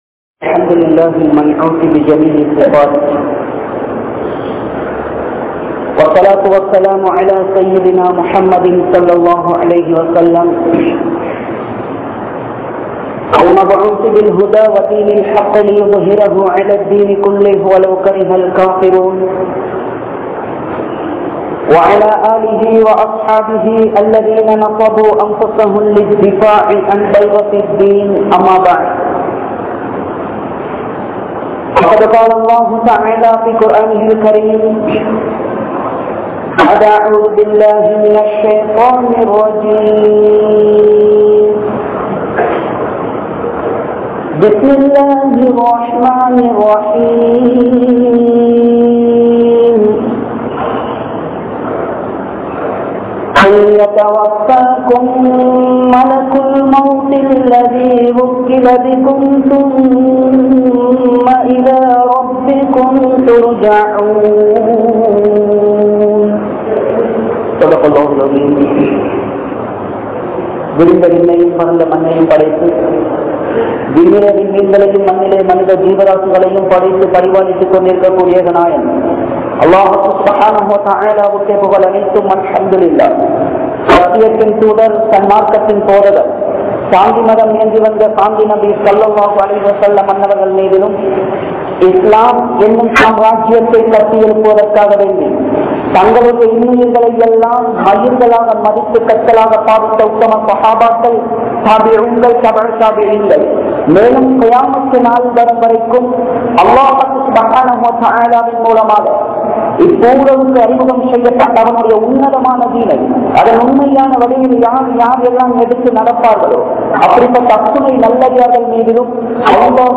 Maranathin Vali (மரணத்தின் வலி) | Audio Bayans | All Ceylon Muslim Youth Community | Addalaichenai